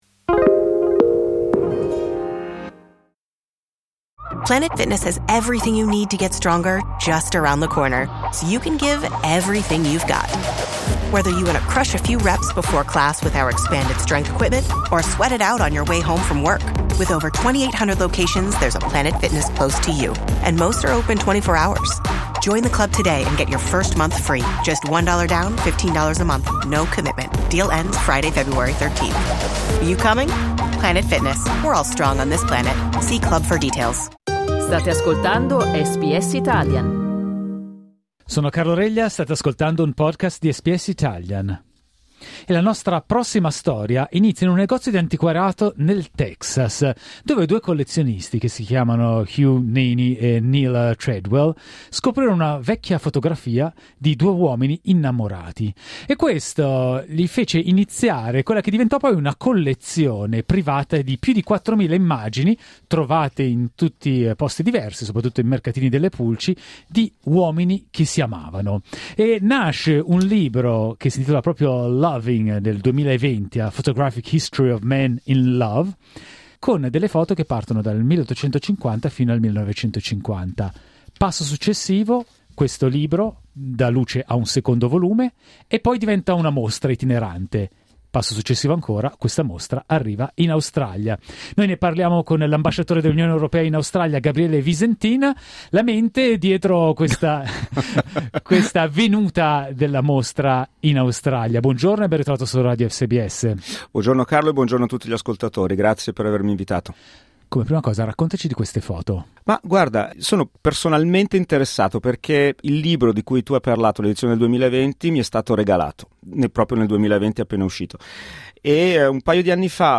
Ascolta l'intervista all'ambasciatore dell'UE Gabriele Visentin cliccando sul tasto "play" in alto a sinistra